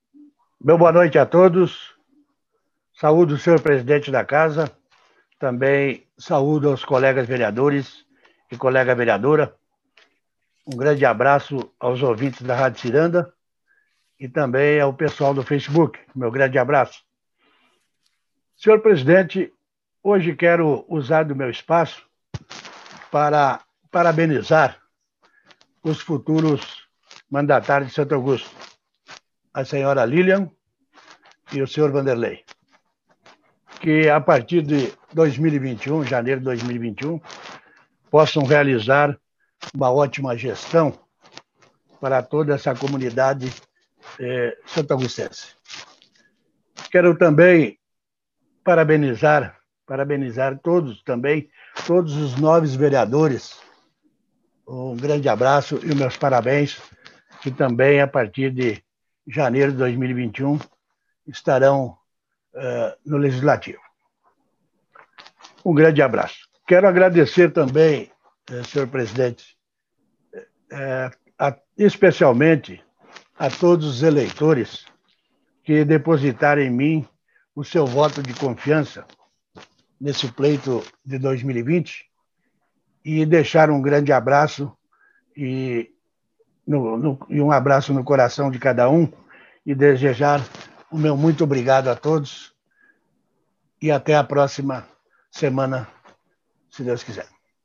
Oradores do Expediente (40ª Ordinária da 4ª Sessão Legislativa da 14ª Legislatura)